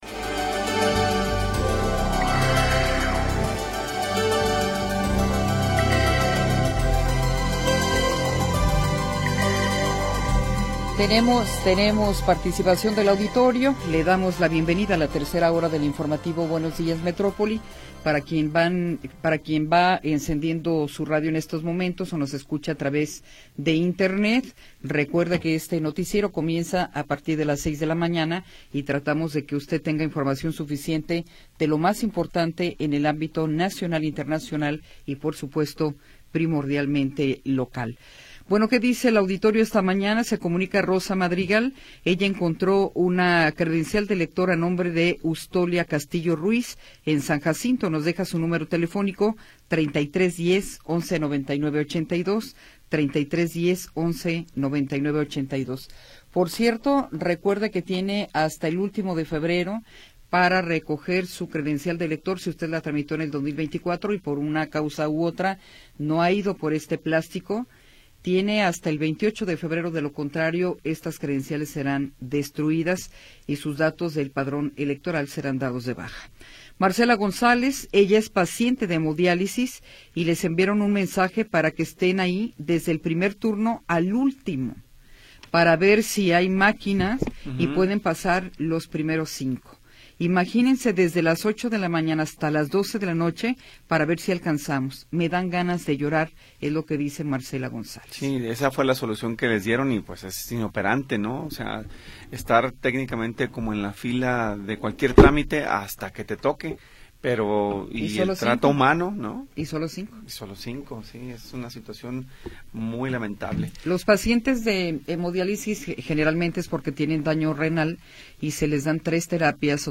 Información oportuna y entrevistas de interés
Tercera hora del programa transmitido el 29 de Enero de 2026.